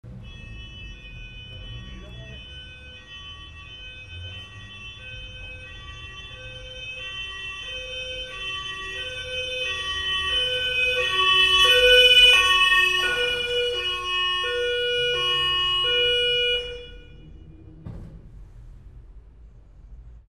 На этой странице вы можете скачать и послушать онлайн различные звуки сирены скорой помощи.